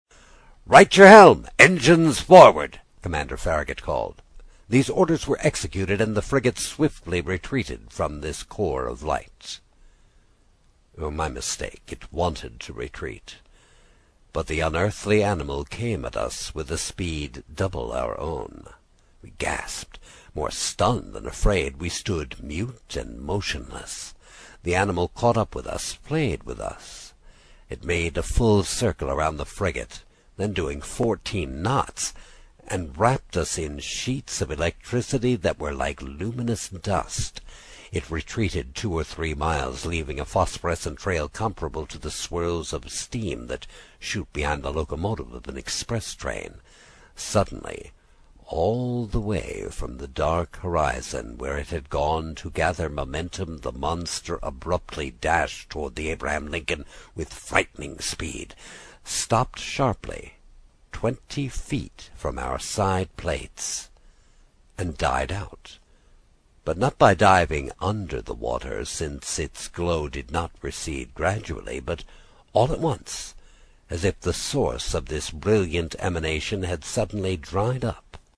英语听书《海底两万里》第61期 第6章 开足马力(3) 听力文件下载—在线英语听力室
在线英语听力室英语听书《海底两万里》第61期 第6章 开足马力(3)的听力文件下载,《海底两万里》中英双语有声读物附MP3下载